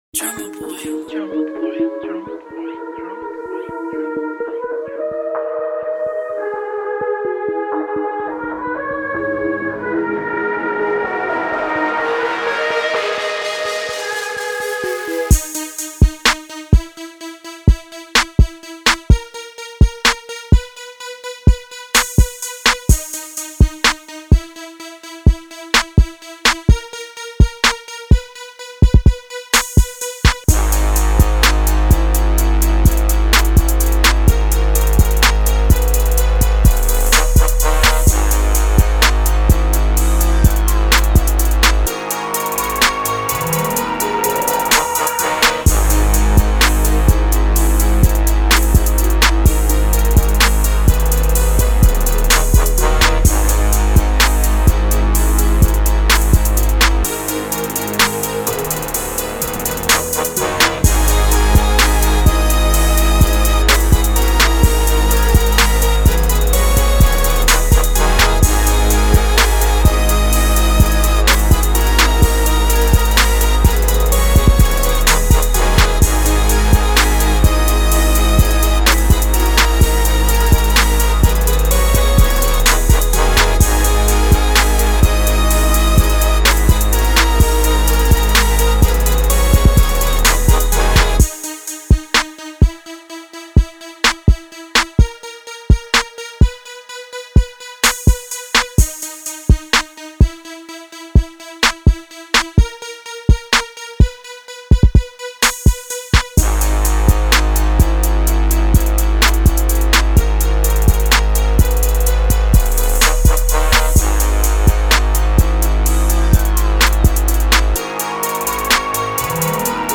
Snares, Hits, Claps, Pianos,